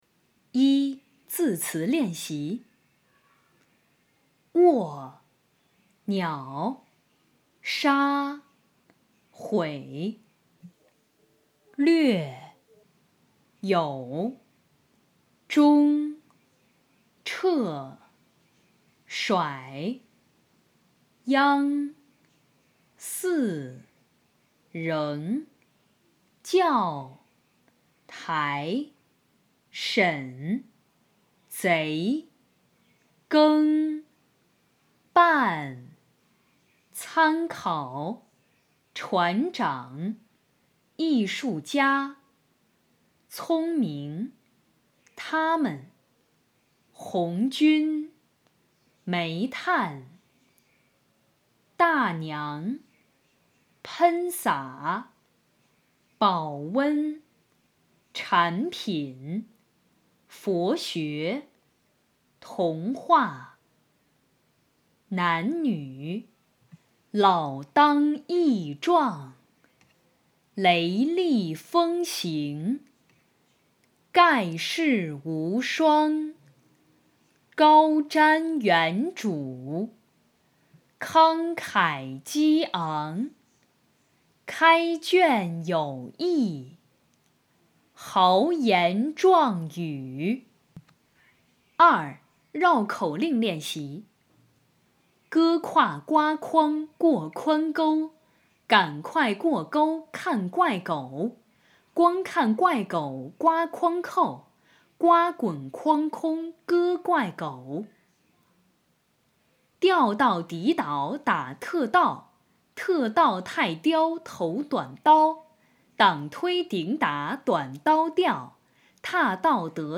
领读课件
课件文本（字词、绕口令）